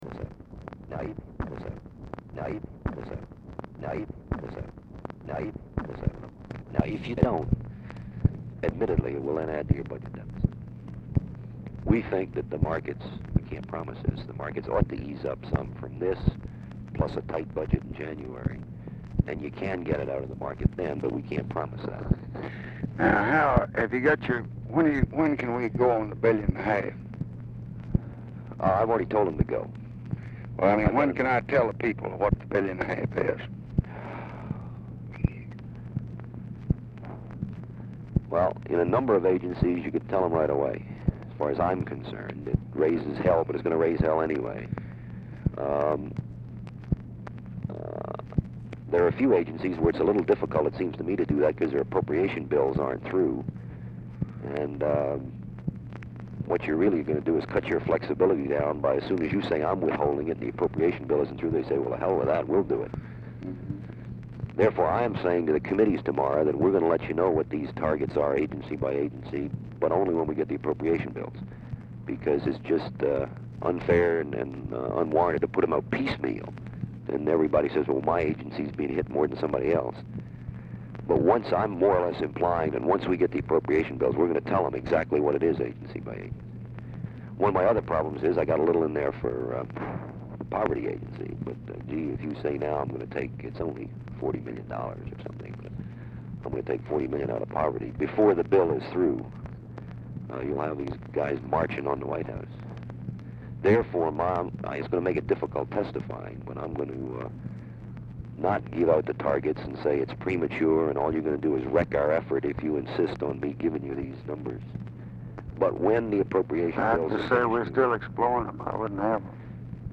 LBJ SPEAKS TO SOMEONE IN THE ROOM DURING HIS CONVERSATION WITH SHULTZE
Format Dictation belt
Location Of Speaker 1 Mansion, White House, Washington, DC
Specific Item Type Telephone conversation